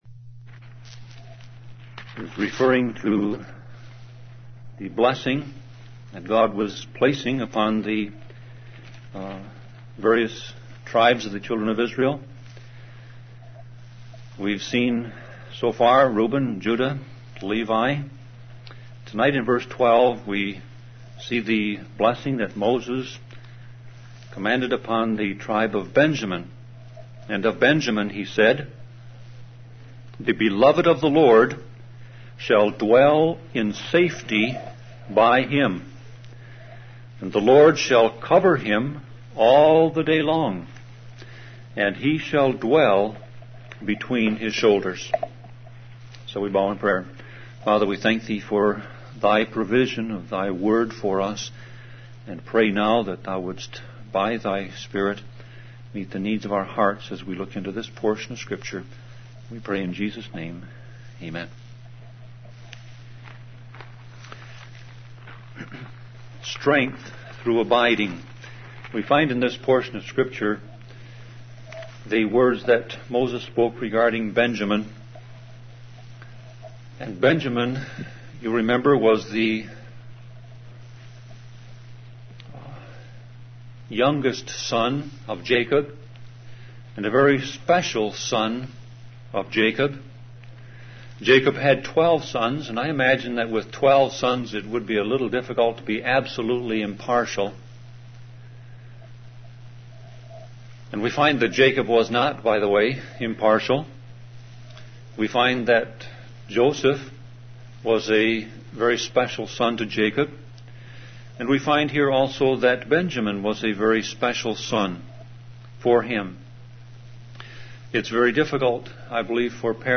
Sermon Audio Passage: Deuteronomy 33:12 Service Type